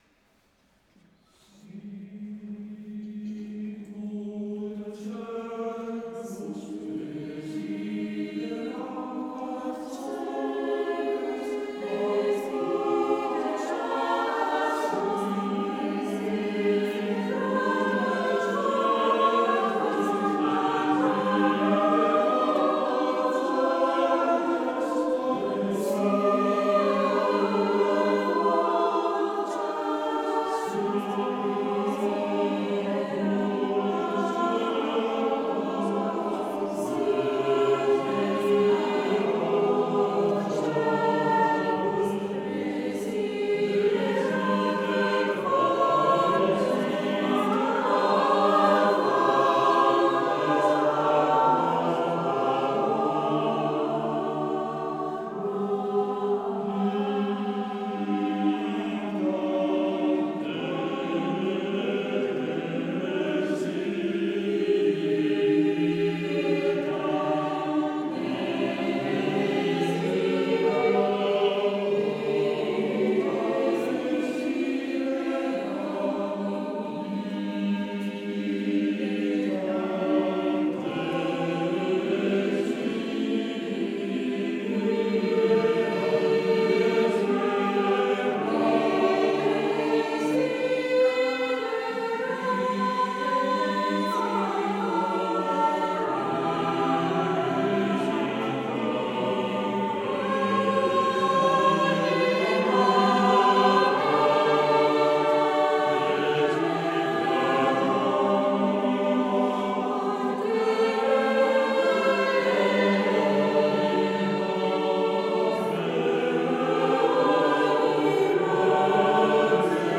Elevazione Musicale Santa Pasqua 2025.
Basilica di S.Alessandro in Colonna, Bergamo
4 voci miste